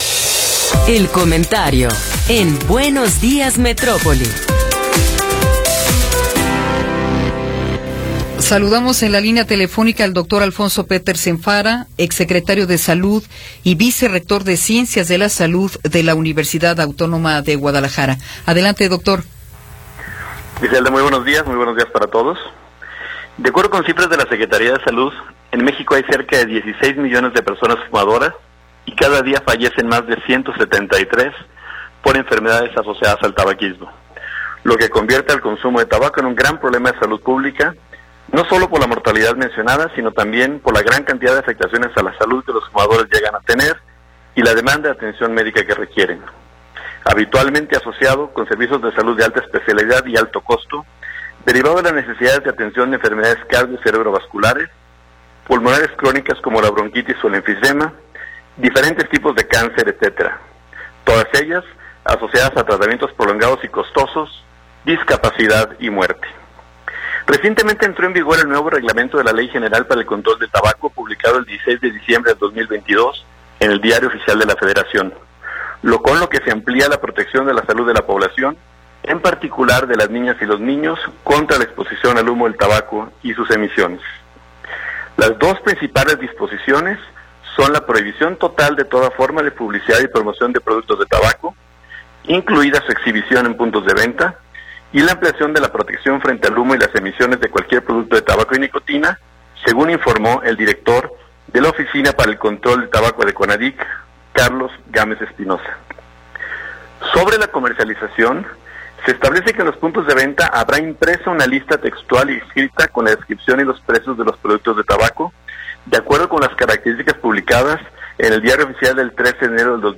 Comentario de Alfonso Petersen Farah – 18 de Enero de 2023 | Notisistema
El Dr. Alfonso Petersen Farah, vicerrector de ciencias de la salud de la UAG y exsecretario de salud del estado de Jalisco, nos habla sobre el tabaquismo y sus afectaciones a la salud.